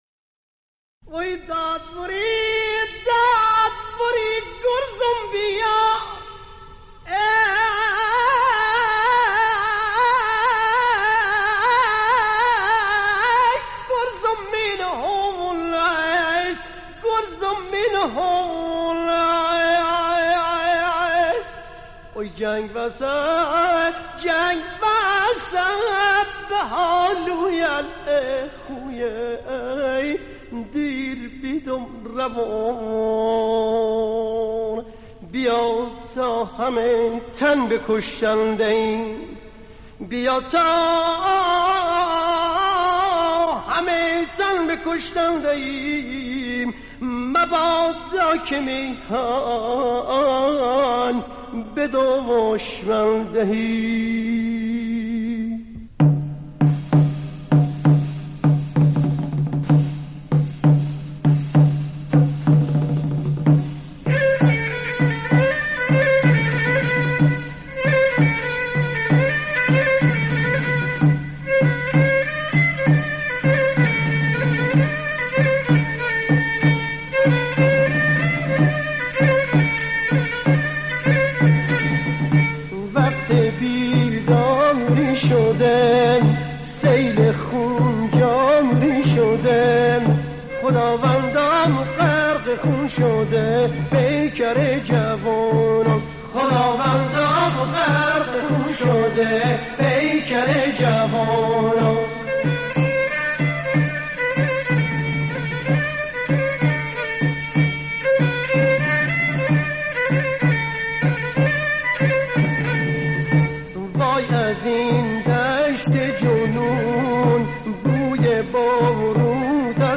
اجرای آواز و تصنیف به گویش دزفولی و با مضمون دفاع مقدس